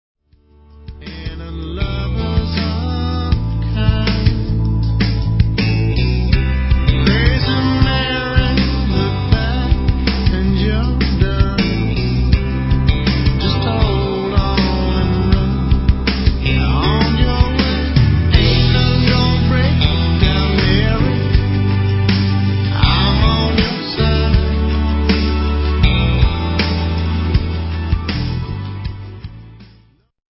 LEAD VOCALS/GUITARS
DRUMS/PERCUSSION/VOCALS
BASS/VOCALS
KEYBOARDS/PEDAL STEEL